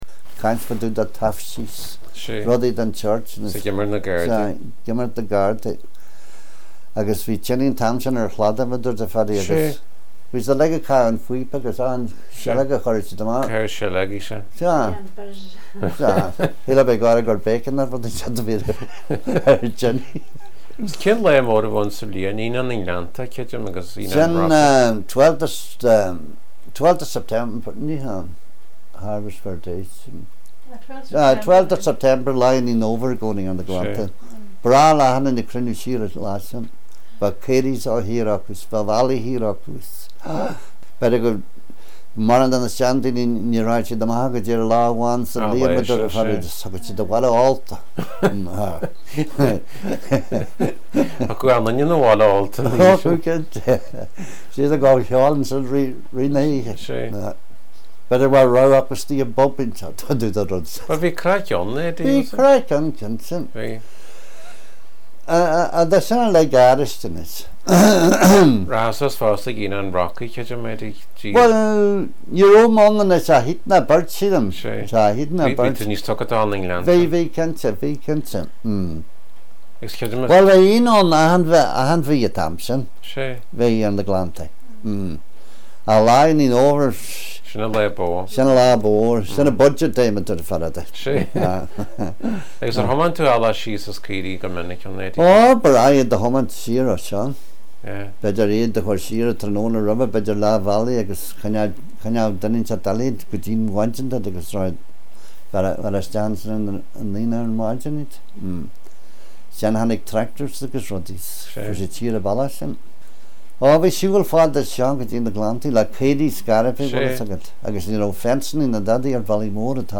Sonraí an agallaimh/na n-agallamh